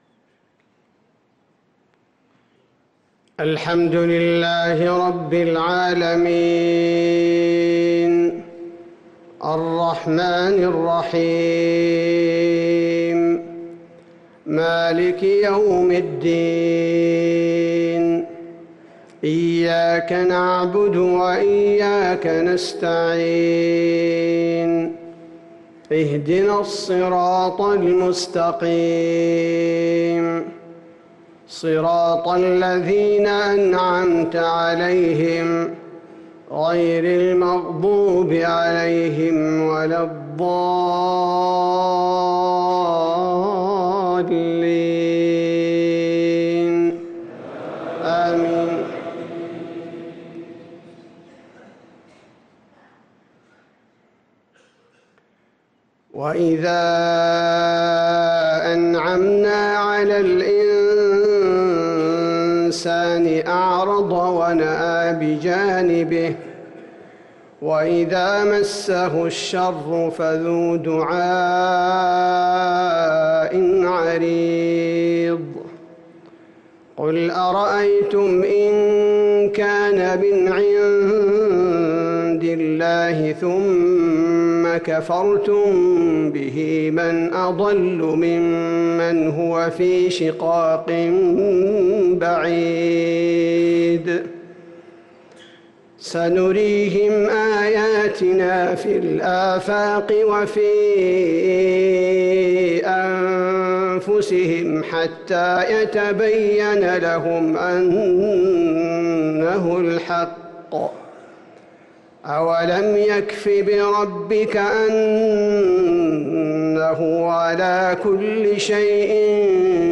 صلاة المغرب للقارئ عبدالباري الثبيتي 18 جمادي الأول 1445 هـ
تِلَاوَات الْحَرَمَيْن .